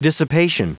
Prononciation du mot dissipation en anglais (fichier audio)
Prononciation du mot : dissipation